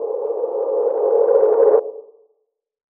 AV_CreepyReverse_FX
AV_CreepyReverse_FX.wav